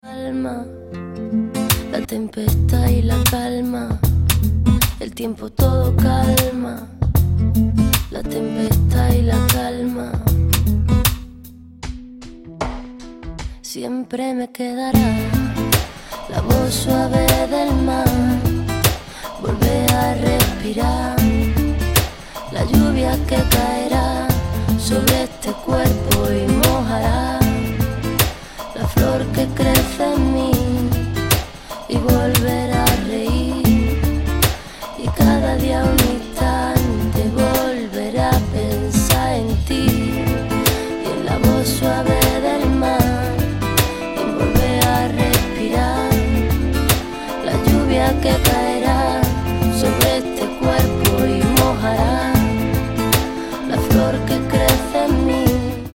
• Качество: 128, Stereo
гитара
женский вокал
спокойные
чувственные
Latin Pop